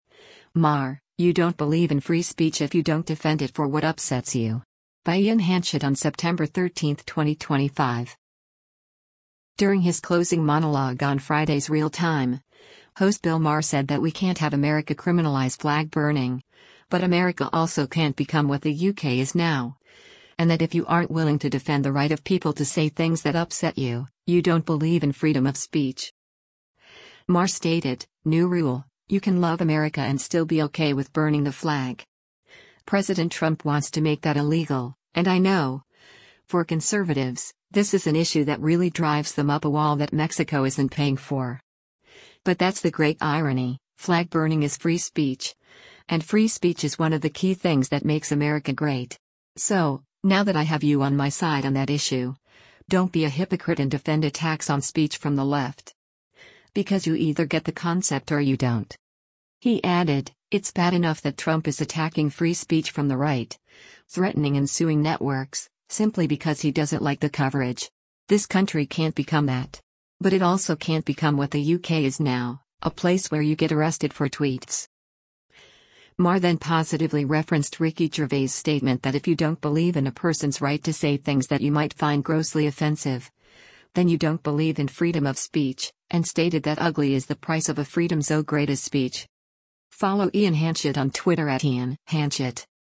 During his closing monologue on Friday’s “Real Time,” host Bill Maher said that we can’t have America criminalize flag burning, but America “also can’t become what the U.K. is now,” and that if you aren’t willing to defend the right of people to say things that upset you, you don’t believe in freedom of speech.